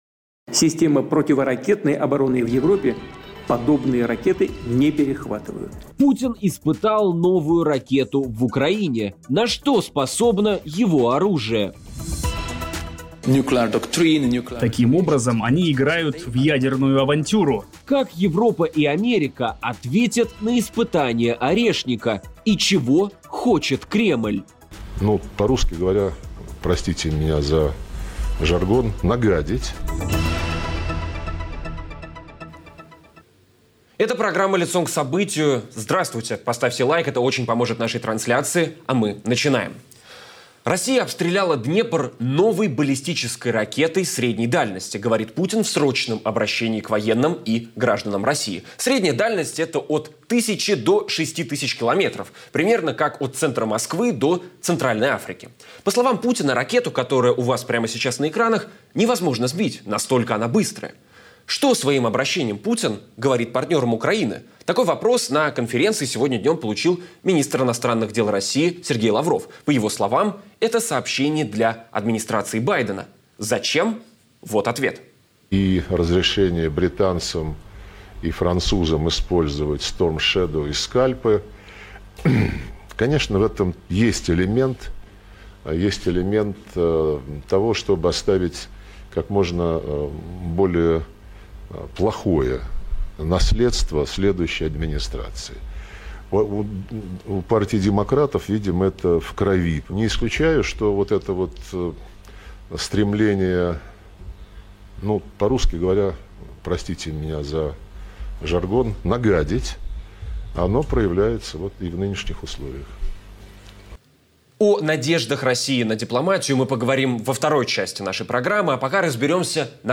В ежедневном режиме анализируем главные события дня. Все детали в прямом эфире, всегда Лицом к Событию
Все детали в прямом эфире, всегда Лицом к Событию … continue reading 1151 epizódok # Обсуждение Новостей # Радио Свобода # Новости